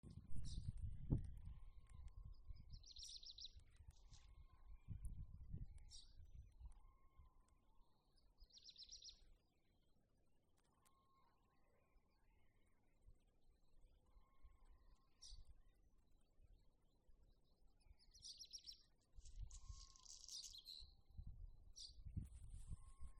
Birds -> Thrushes ->
Black Redstart, Phoenicurus ochruros